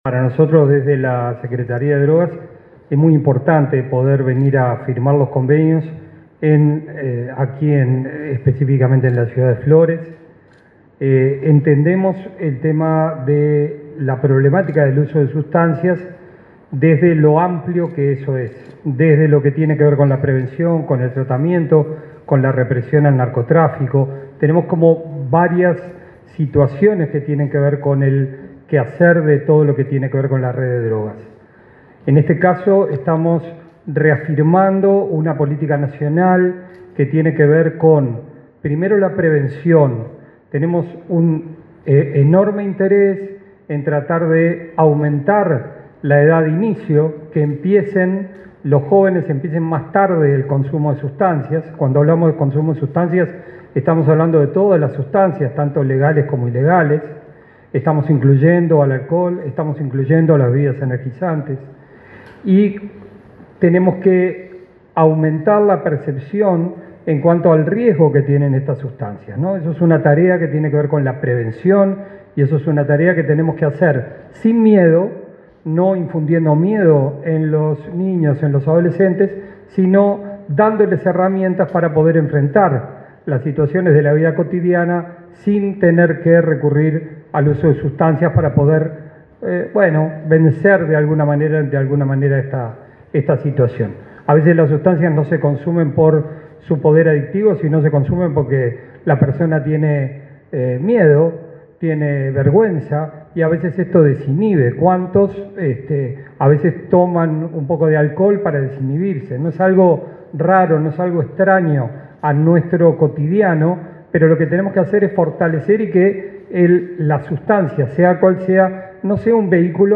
El presidente de la Junta Nacional de Drogas (JND), Jorge Díaz, y el secretario general de Drogas, Gabriel Rossi, se expresaron en Flores, durante un